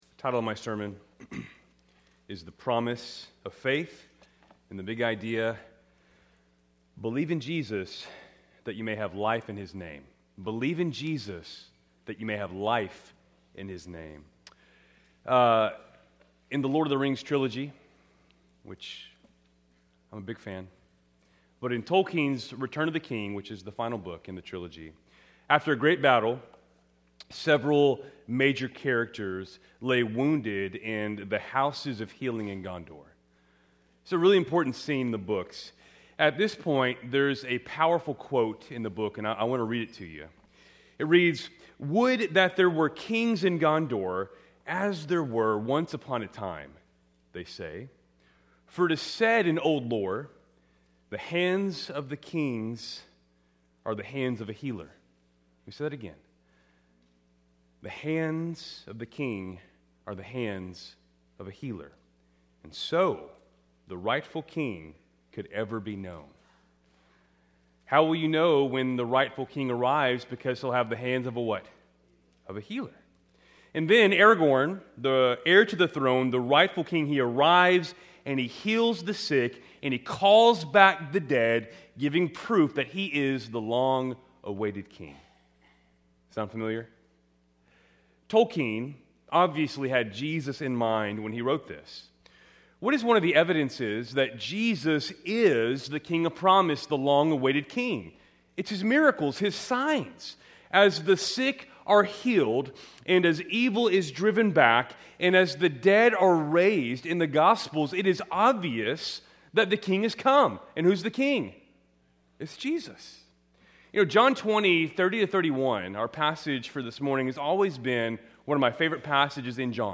Keltys Worship Service, March 29, 2026